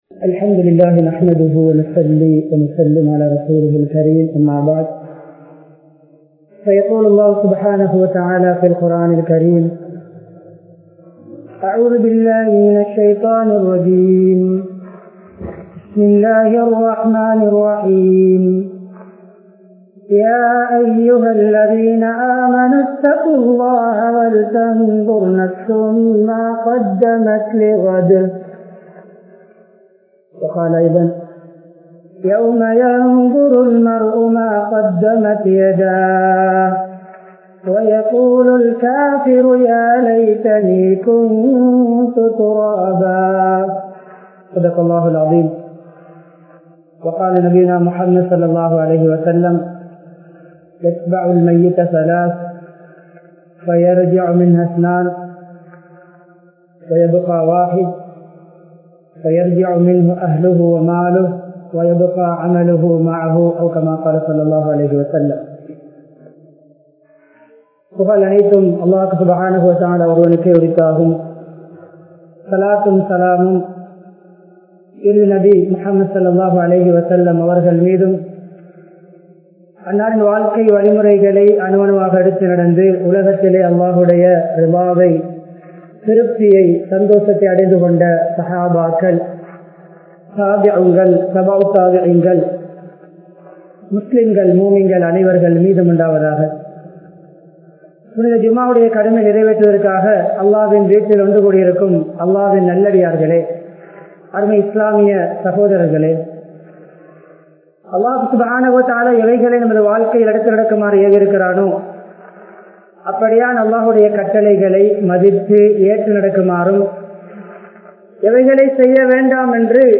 Marumaikkaaha Enna Seithulloam? (மறுமைக்காக என்ன செய்துள்ளோம்?) | Audio Bayans | All Ceylon Muslim Youth Community | Addalaichenai
Colombo, Kolonnawa Jumua Masjidh